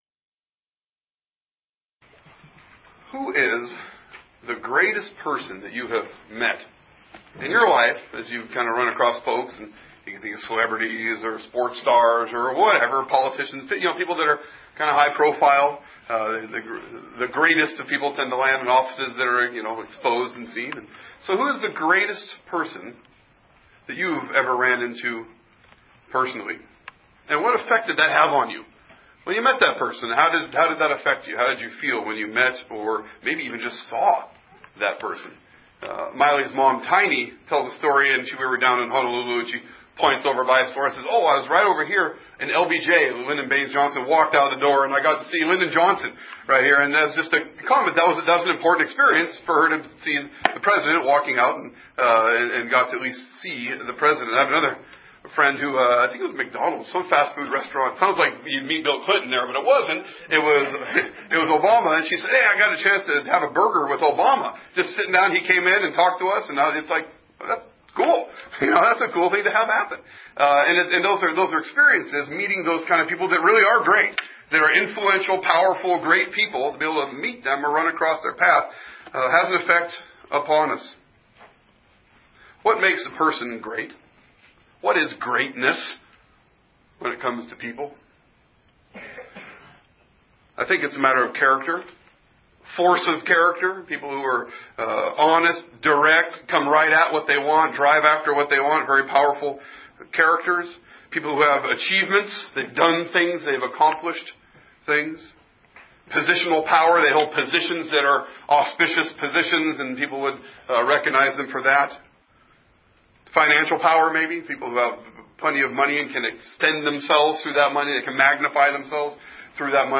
2017 The Kingly Ministry The Priestly Ministry The Prophetic Ministry rev Revelation commission conquering king Sunday Morning Worship Scripture